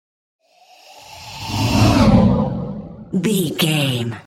Whoosh monster breath
Sound Effects
Atonal
scary
ominous
eerie